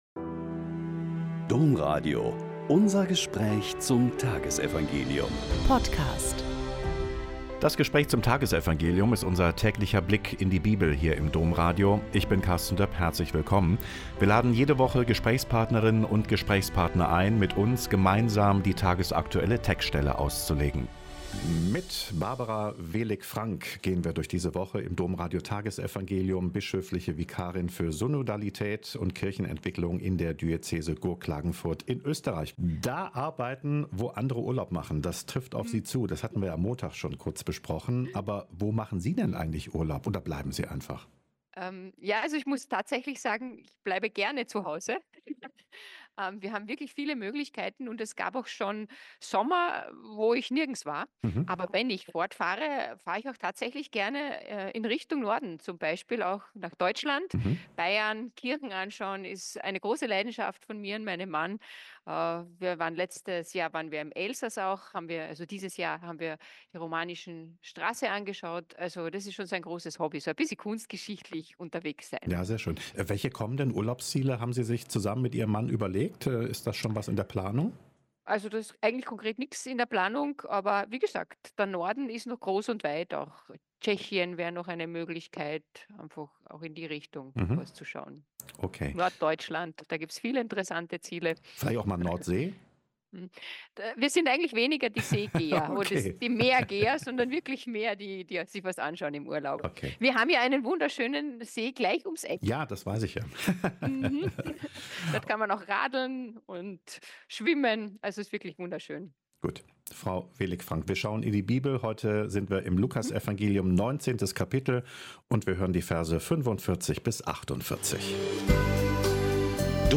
Lk 19,45-48 - Gespräch